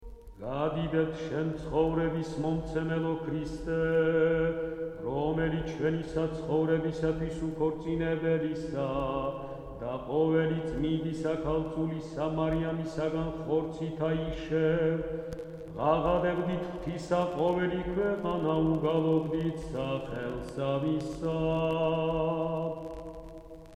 Christmas carols
Keywords: ქართული ხალხური სიმღერა